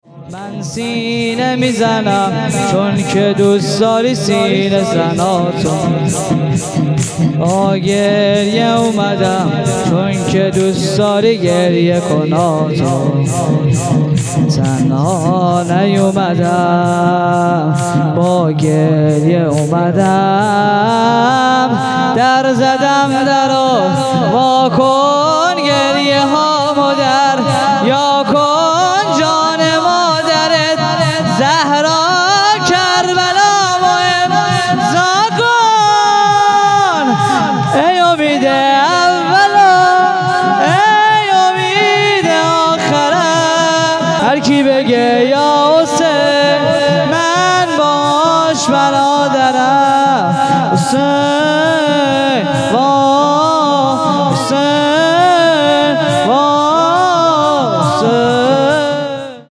شور پایانی | من سینه میزنم
جلسه هفتگی 1404/3/5